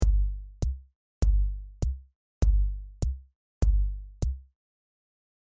FishMarket_hat-and-kik-and-tom-1.mp3